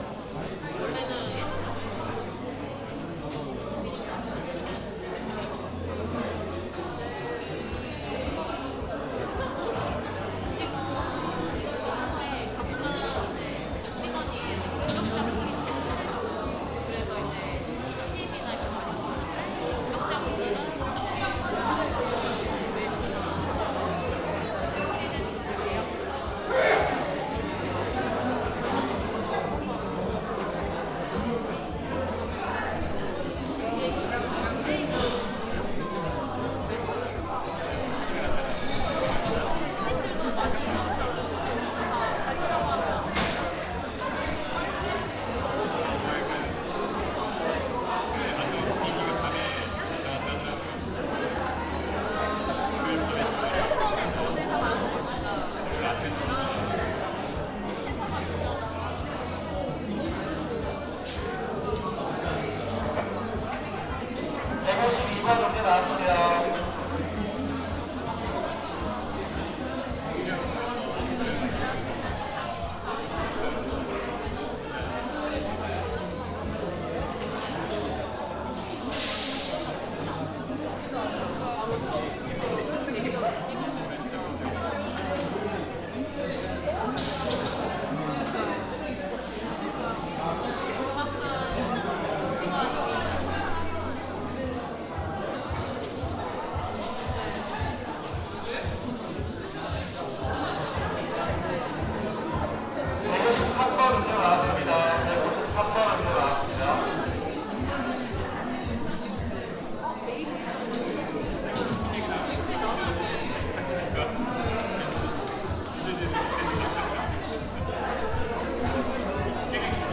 카페시끄러움.mp3